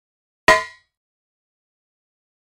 Звуки удара металлической трубой
Звук удара ключом по чугунной трубе